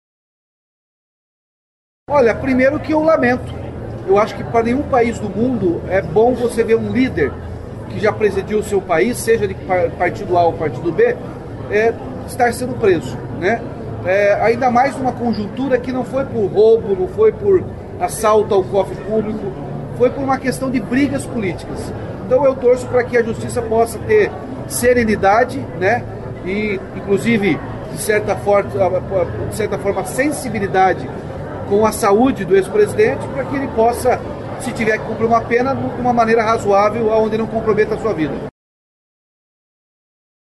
O governador do Paraná, Ratinho Junior (PSD), voltou a comentar a prisão do ex-presidente Jair Bolsonaro (PL) durante entrevista coletiva nesta terça-feira (25), em Curitiba, no lançamento de uma nova etapa do programa de intercâmbio Ganhando o Mundo.